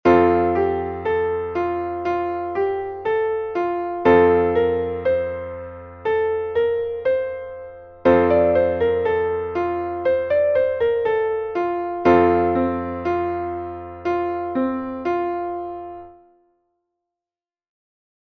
Traditionelles Kinderlied (Kanon aus Frankreich)